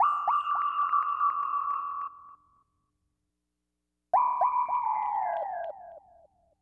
synthFX.wav